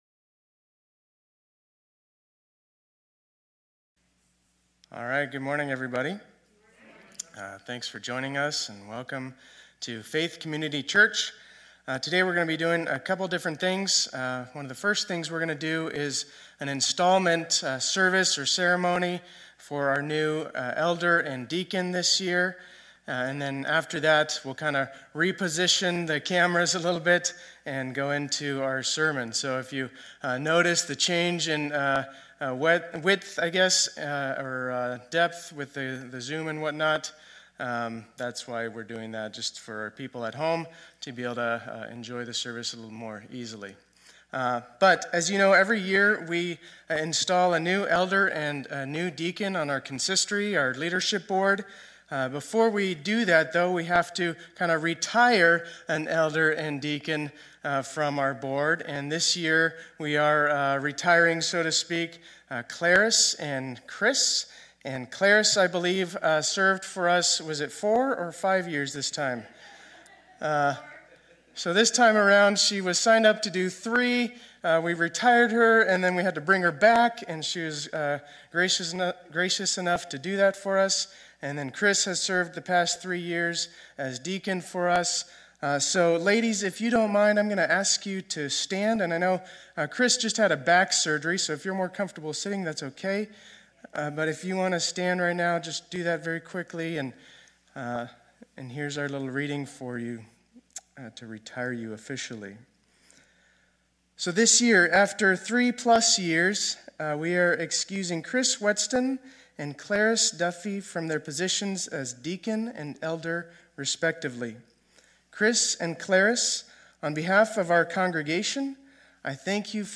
2021-01-17 Sunday Service
Installation of Deacon and Elder plus Sermon